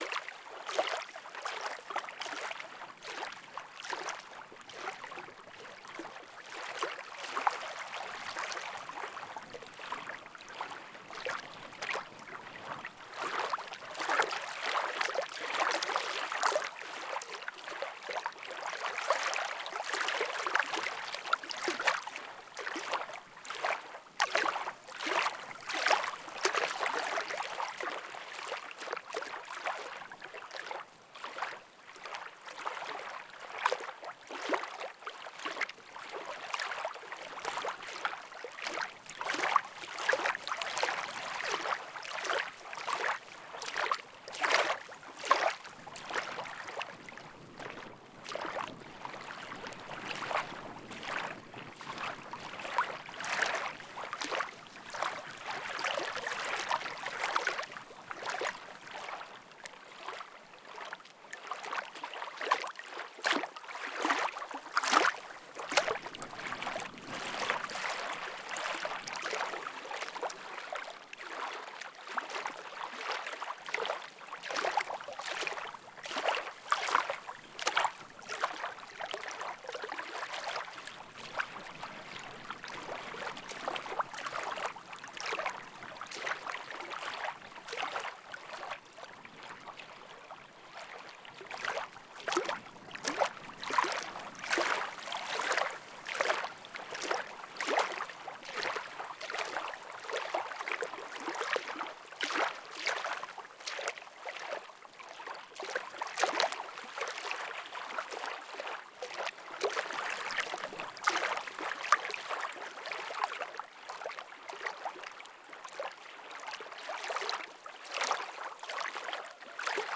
son berges du lac
water_lake.wav